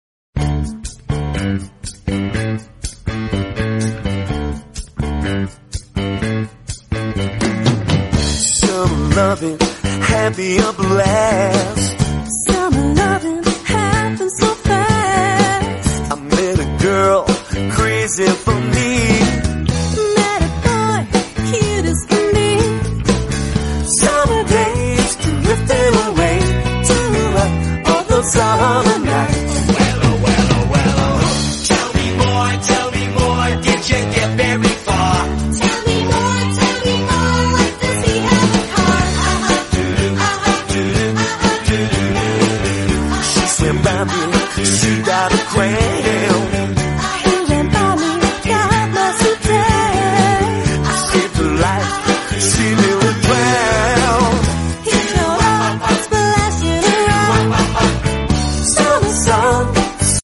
ASMR No Talking. Sem Falar Sound Effects Free Download